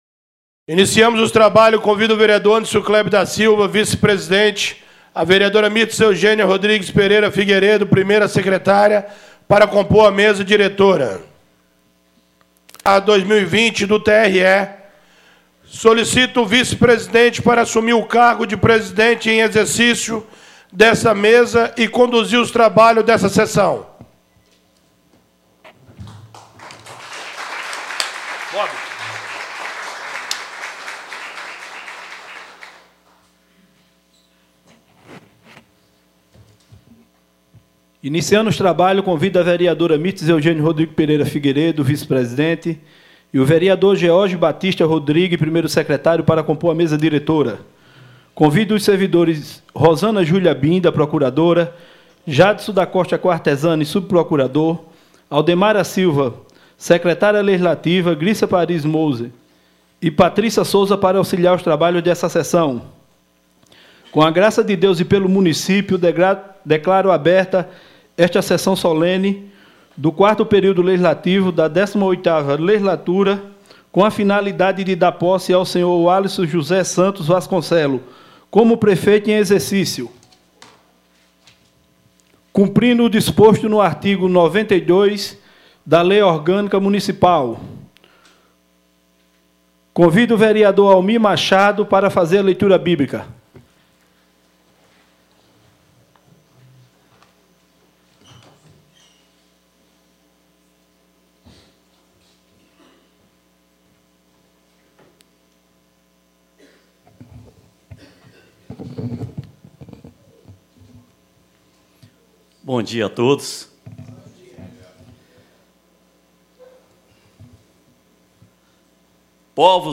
Sessão Solene posse Prefeito e Presidente Câmara dia 13 de março de 2020